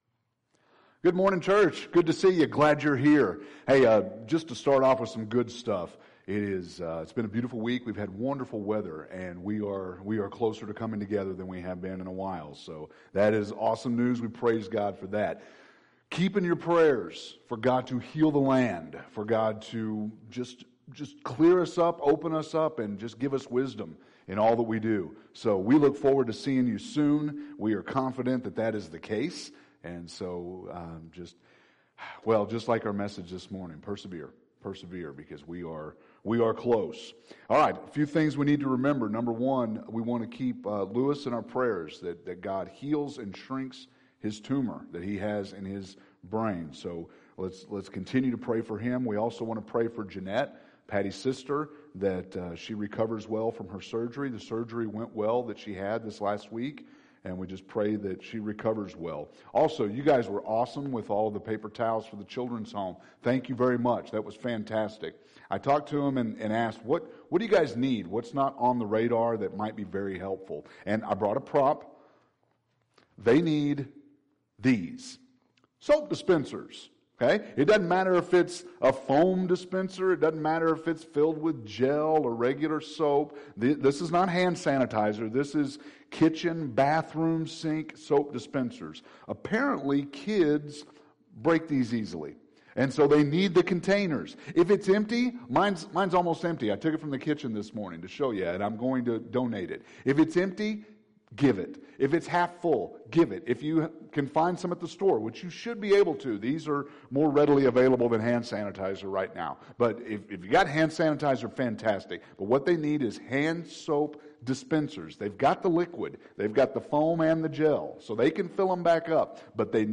May 3rd – Sermons